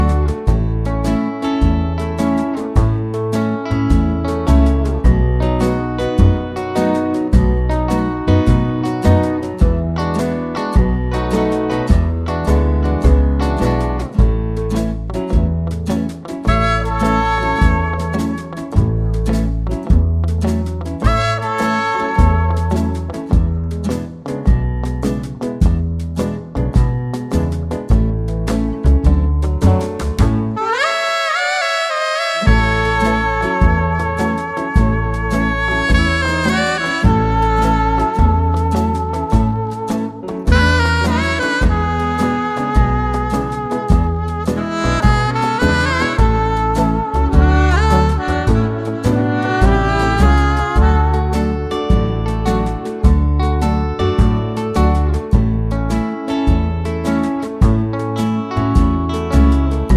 no Backing Vocals Easy Listening 2:27 Buy £1.50